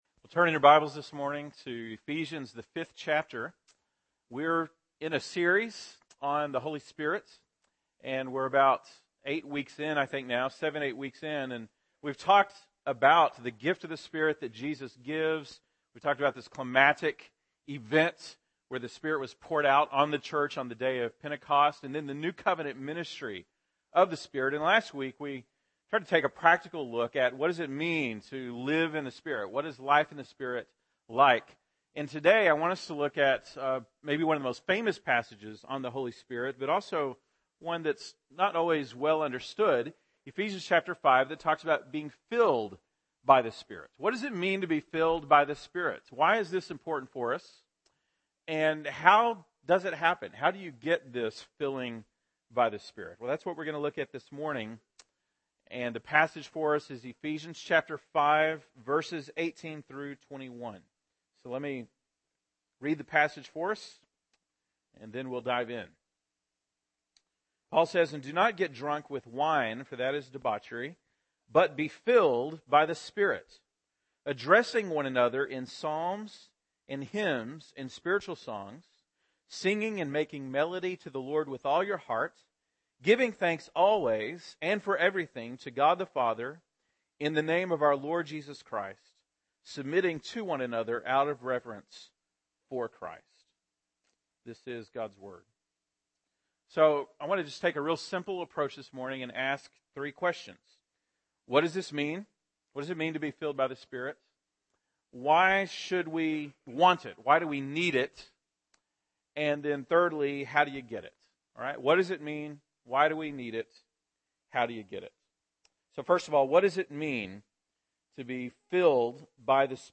November 24, 2013 (Sunday Morning)